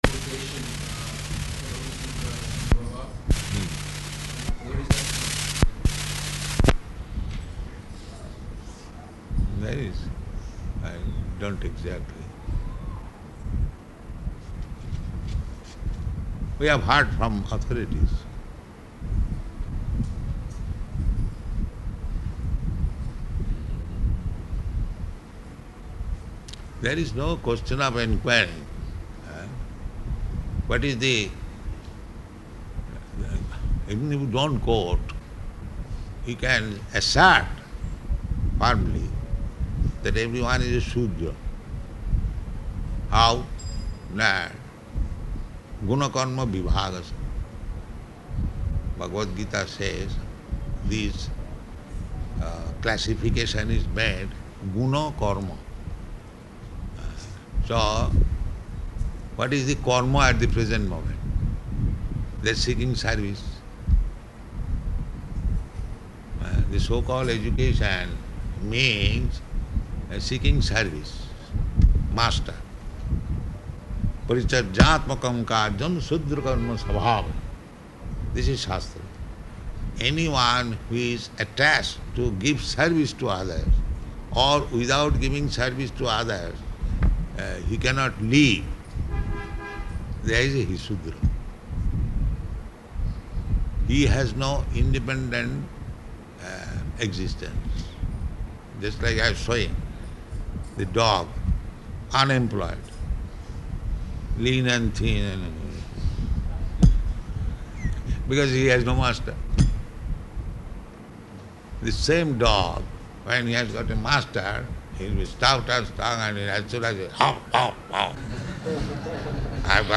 Bhagavad-gītā 4.13 --:-- --:-- Type: Bhagavad-gita Dated: August 5th 1974 Location: Vṛndāvana Audio file